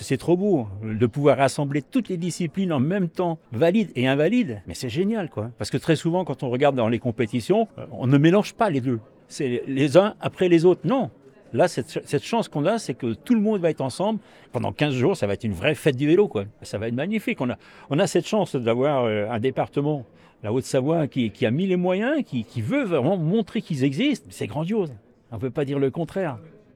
Les principaux acteurs de l’organisation, les élus ainsi que de nombreux athlètes se sont réunis, ce jeudi 23 avril 2026, au Congrès Impérial, à Annecy, pour présenter les contours de cet évènement spectaculaire. 14 sites ont été retenus et confirmés sur le département, avec le vélodrome de Saint-Quentin-en-Yvelines.